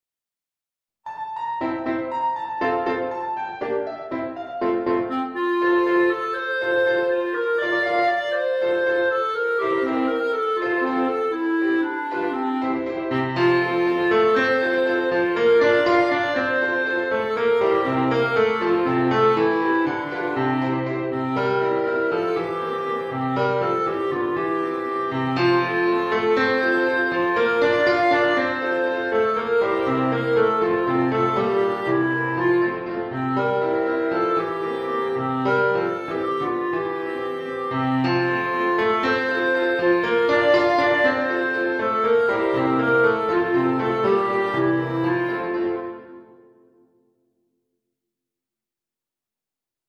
Allegro giocoso